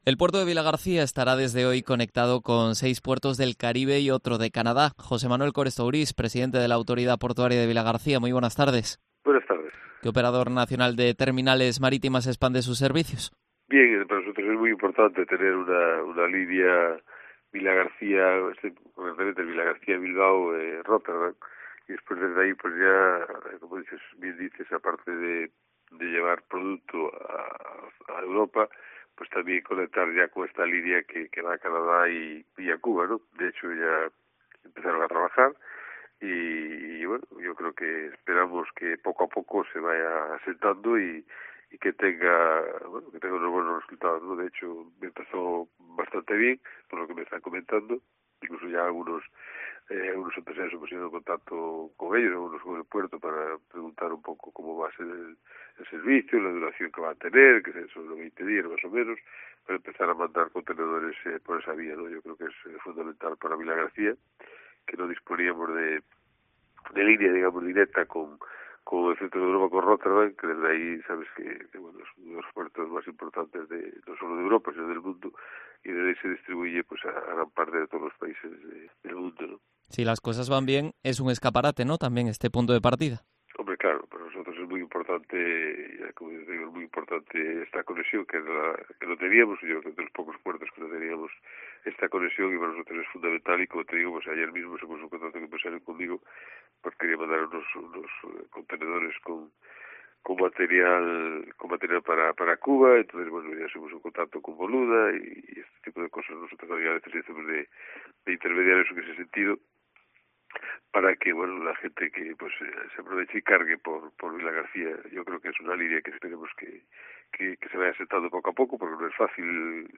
Entrevista a José Manuel Cores Tourís, presidente de la Autoridad Portuaria de Vilagarcía